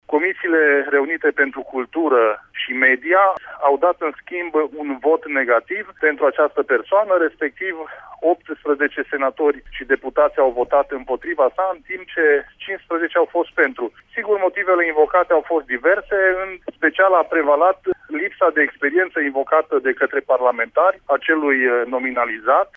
Vicepreședintele comisiei pentru cultură și media din camera superioară a Parlamentului, senatorul PNL de Mureș, Marius Pașcan: